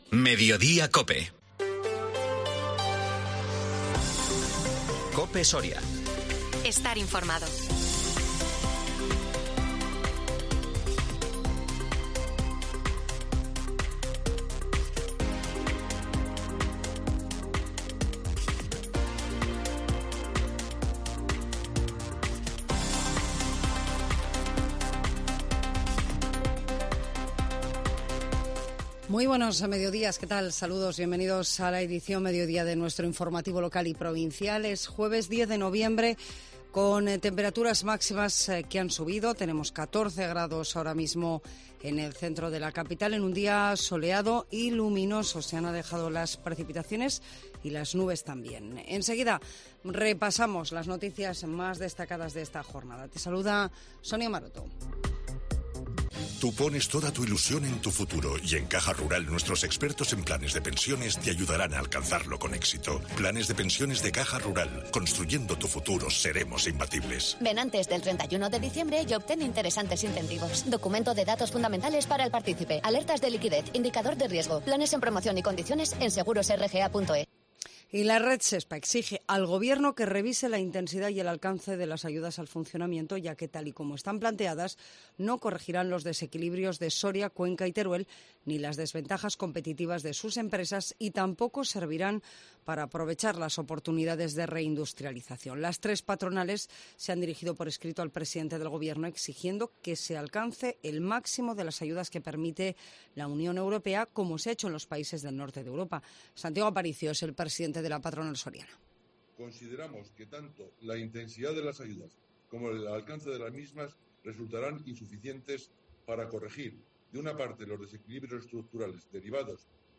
INFORMATIVO MEDIODÍA COPE SORIA 10 NOVIEMBRE 2022